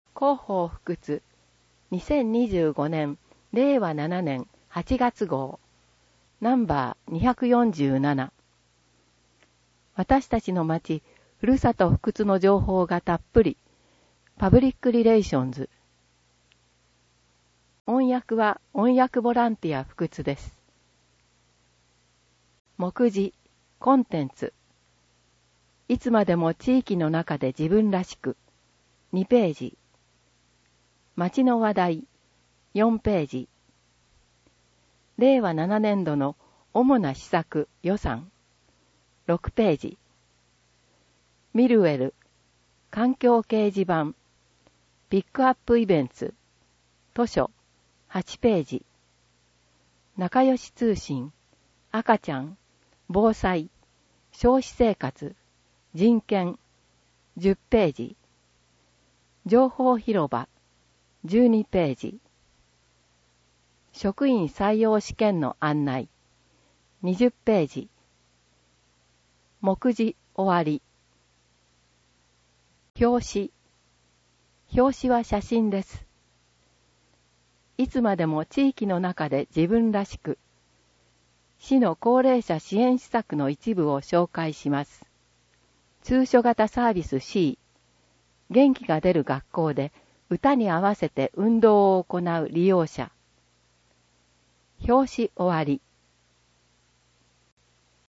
広報ふくつを音声で聞けます
音訳ボランティアふくつの皆さんが、毎号、広報ふくつを音訳してくれています。